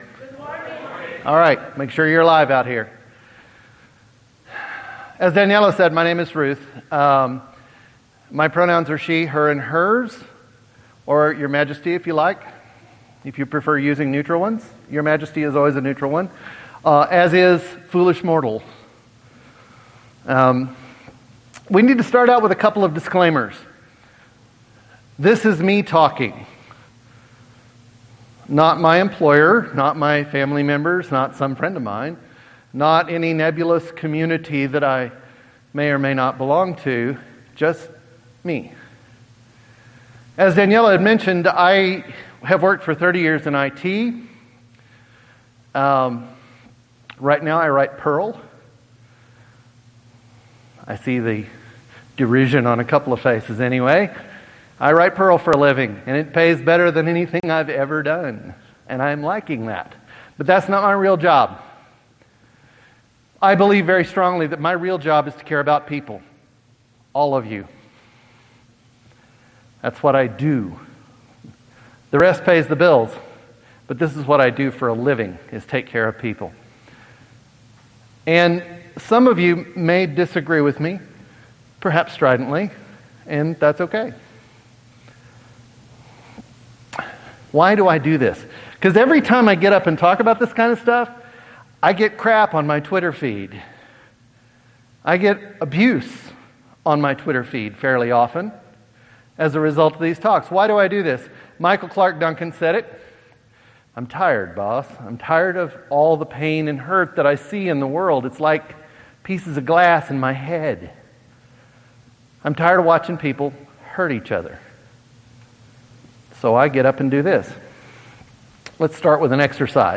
In this talk, you’ll learn the science behind these powerful emotions, and how using them can help you build stronger, more inclusive communities.